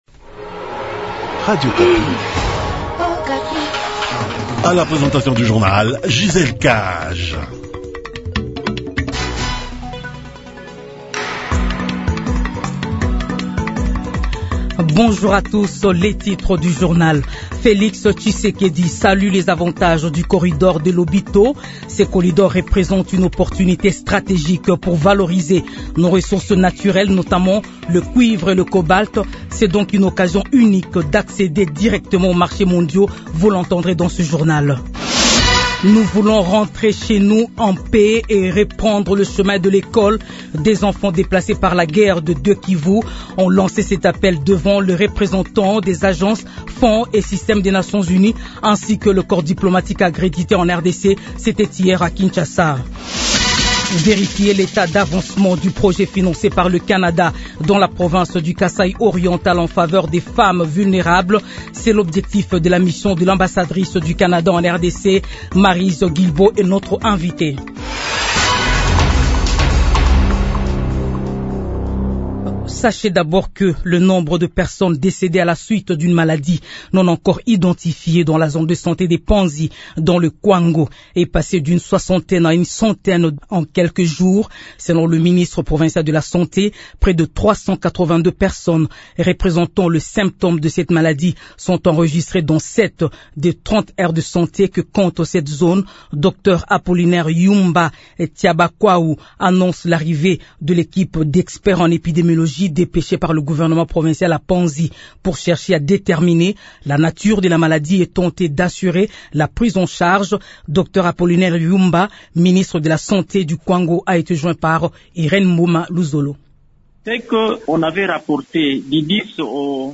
Journal français matin 7 heures